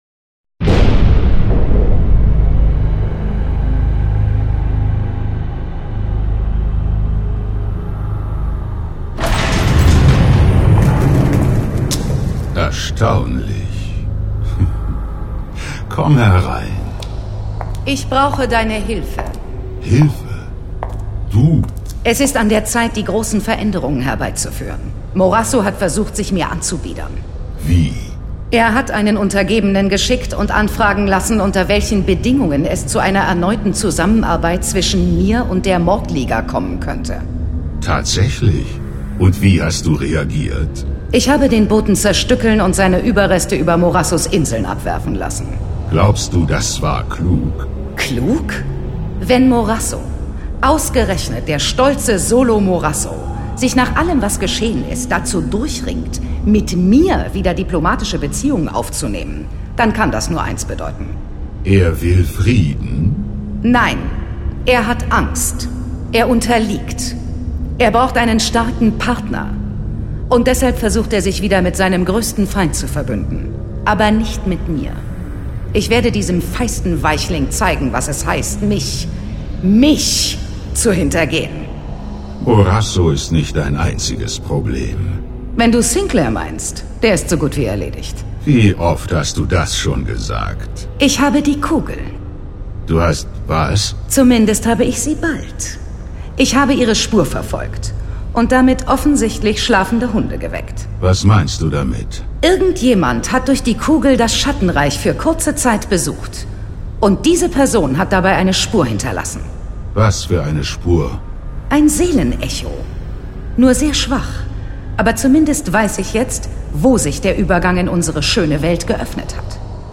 John Sinclair - Folge 60 Ich stieß das Tor zur Hölle auf (I/III). Hörspiel.